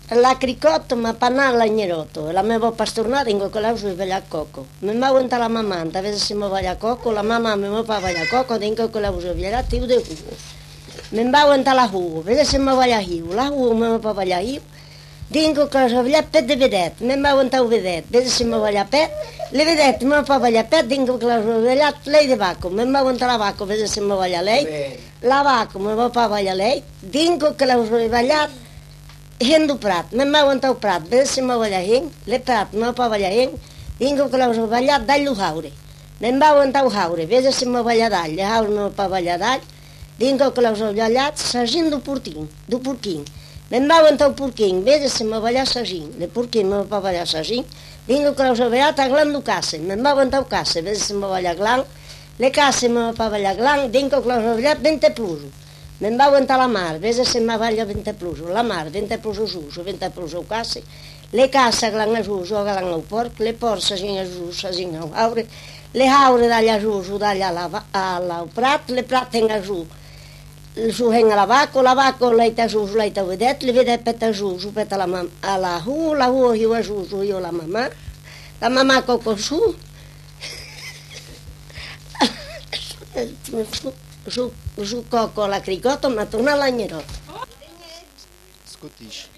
Aire culturelle : Savès
Genre : conte-légende-récit
Effectif : 1
Type de voix : voix de femme
Production du son : récité